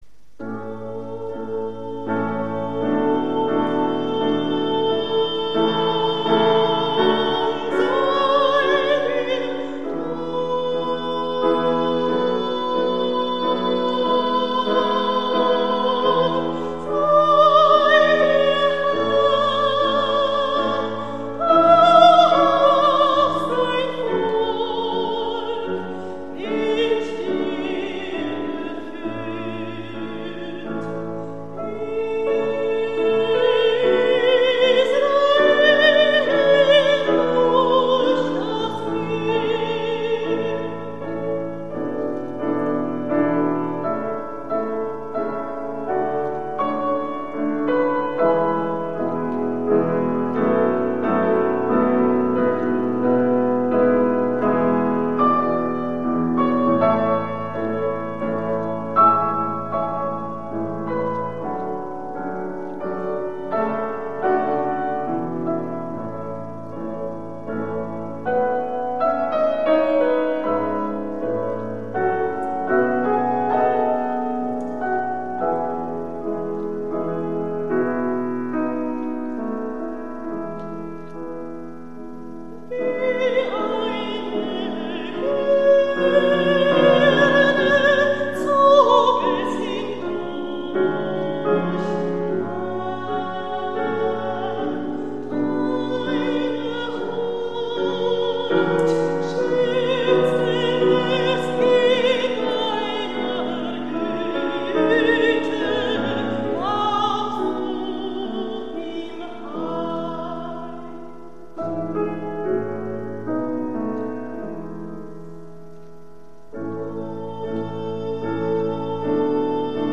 Gesang
Piano Home